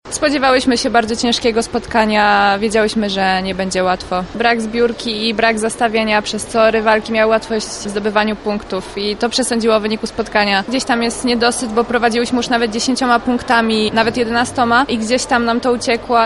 zawodniczka AZS.